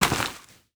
Dirt footsteps 18.wav